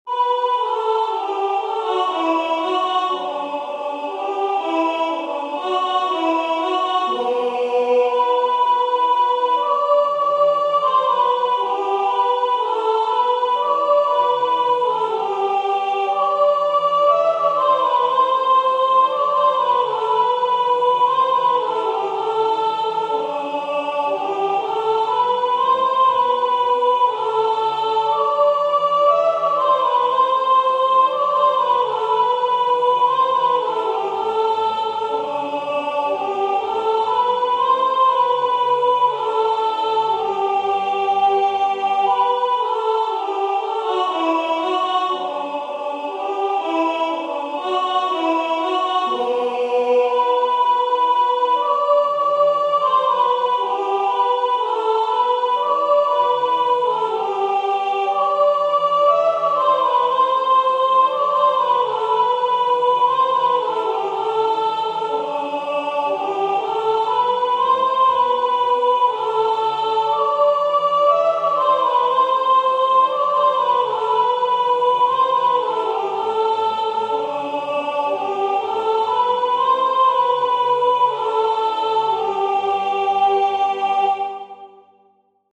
MP3 sop 2   MP3 MP3 MP3 MP3
Angels_from_the_realms_soprano2.mp3